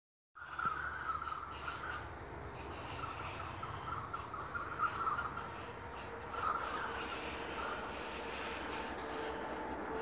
Anó Grande (Crotophaga major)
Nombre en inglés: Greater Ani
Localización detallada: Cercanías del arroyo Santa Ana
Condición: Silvestre
Certeza: Fotografiada, Vocalización Grabada
Crotophaga-major320k.mp3